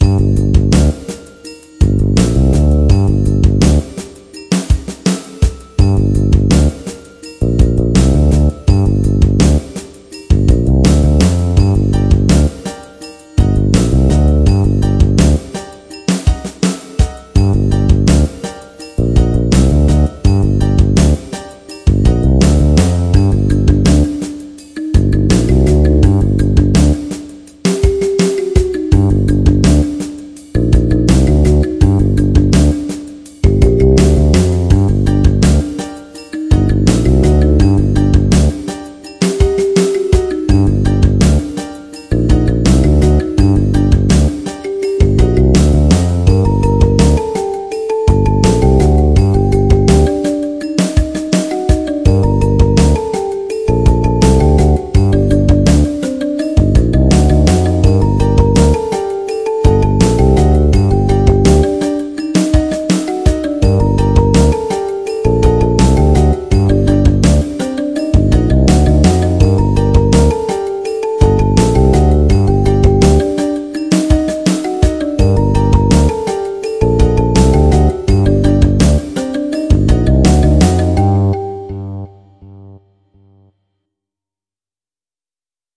Mysterious reggae or dub with a twist.
• Music has an ending (Doesn't loop)
MIDI rendered by Yamaha PSR-S900 keyboard